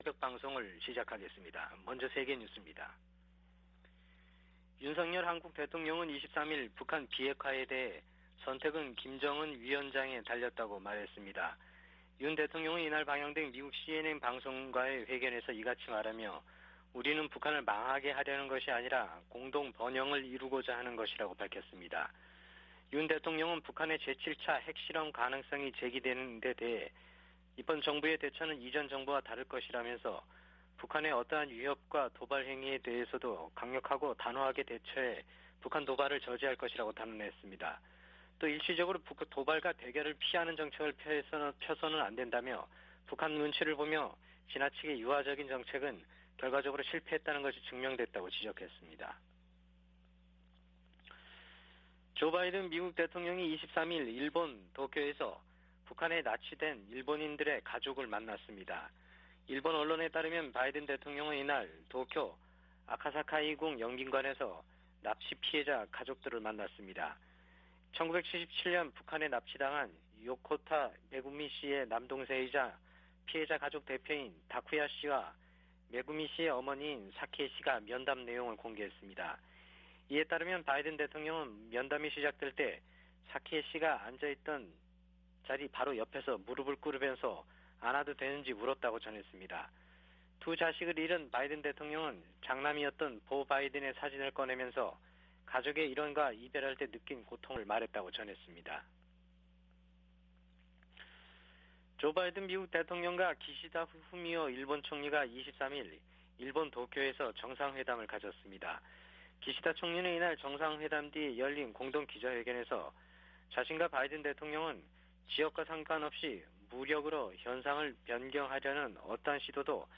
VOA 한국어 '출발 뉴스 쇼', 2022년 5월 24일 방송입니다. 조 바이든 미국 대통령과 기시다 후미오 일본 총리는 북한의 핵과 탄도미사일 프로그램을 규탄했습니다. 바이든 대통령이 미국을 포함한 13개 국가가 참여하는 '인도태평양 경제프레임워크(IPEF)' 출범을 공식 선언했습니다. 워싱턴의 전문가들은 미한동맹이 바이든 대통령의 방한을 계기로 안보동맹에서 기술안보, 글로벌 동맹으로 진화했음을 보여줬다고 평가했습니다.